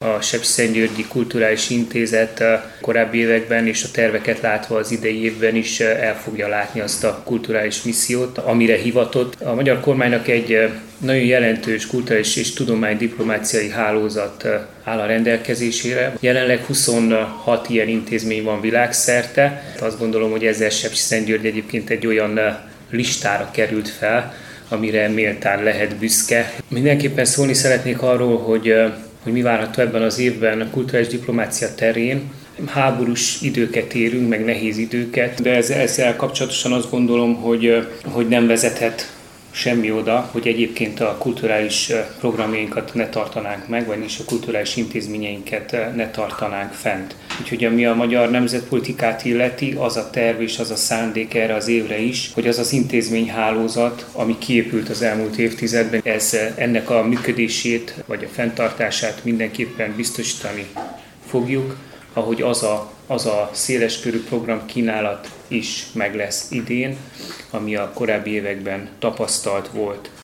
Tóth László, Magyarország csíkszeredai főkonzulja a hétfői, sepsiszentgyörgyi sajtótájékoztatón leszögezte: a Magyar Kormány idén is folytatja a nemzetpolitikai programjait és fenntartja az elmúlt évtizedben kiépített intézményeit.